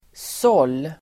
Ladda ner uttalet
Uttal: [sål:]